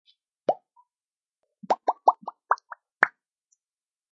Download Bubble Pop sound effect for free.
Bubble Pop